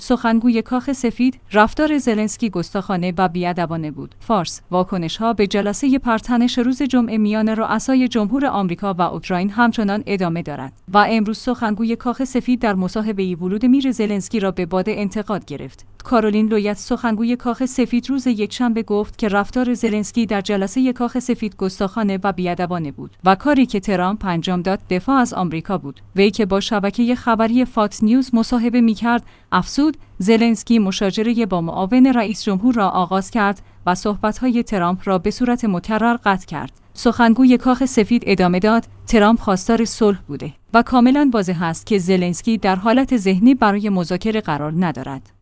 فارس/ واکنش‌ها به جلسه پر تنش روز جمعه میان رؤسای جمهور آمریکا و اوکراین همچنان ادامه دارد و امروز سخنگوی کاخ سفید در مصاحبه‌ای ولودمیر زلنسکی را به باد انتقاد گرفت.